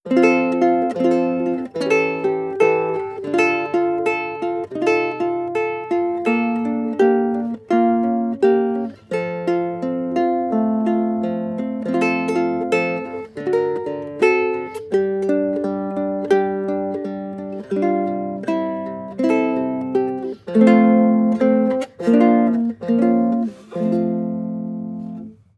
• guitarlele traditional sequence.wav
guitarlele_traditional_sequence_38v.wav